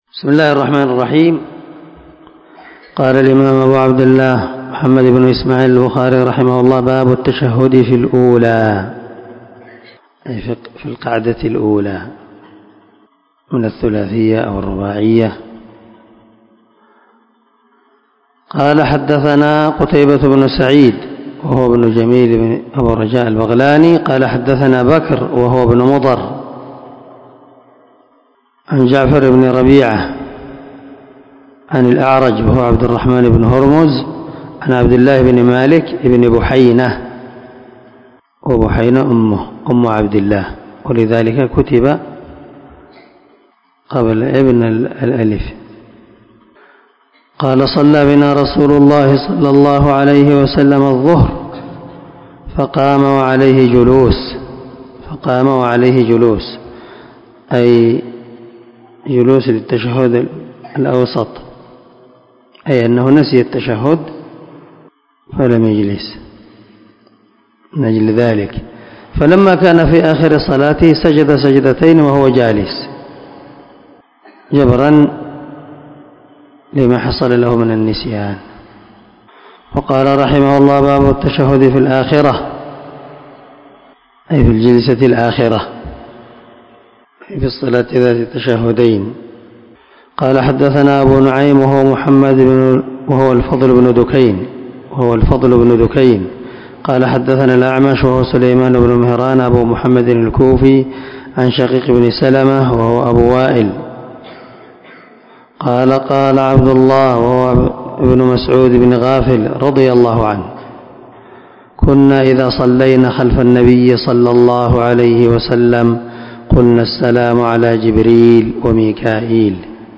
530الدرس 113من شرح كتاب الأذان حديث رقم ( 830 - 831 ) من صحيح البخاري